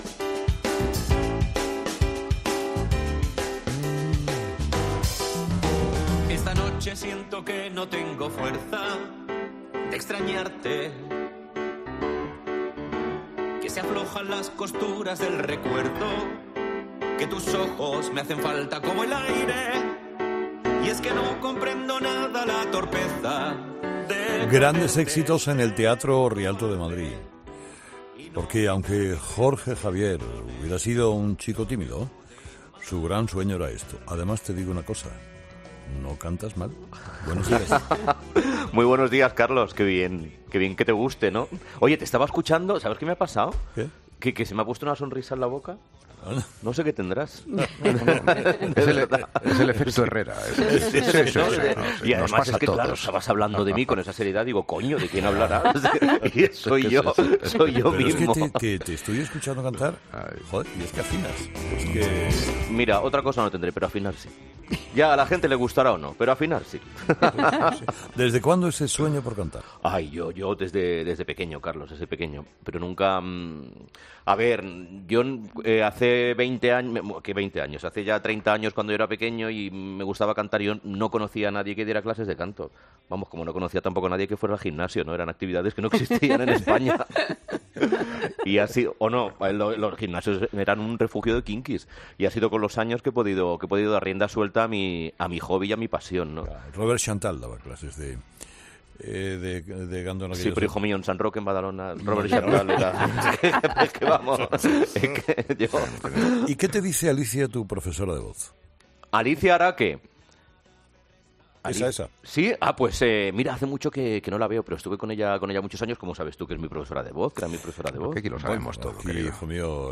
” "Pues no cantas mal", le ha evaluado Carlos Herrera.